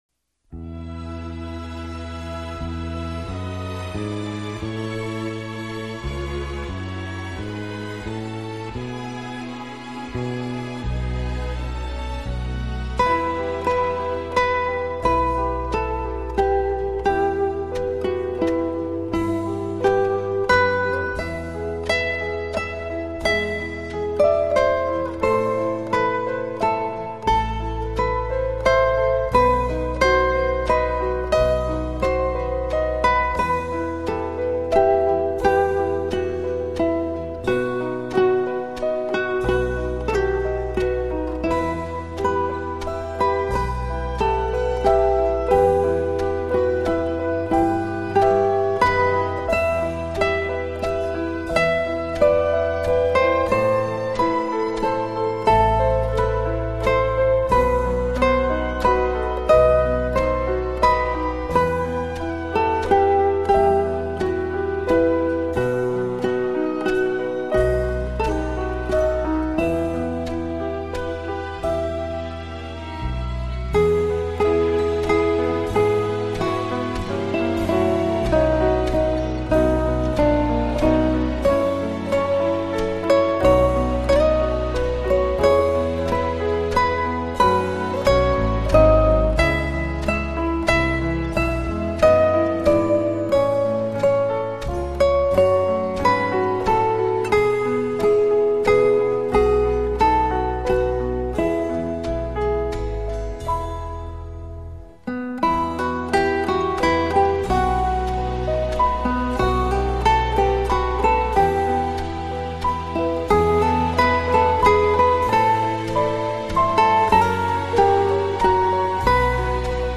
优美轻音乐系列专辑
丽的音乐世界，音乐中曼妙的空间感便自然而然地完全展现！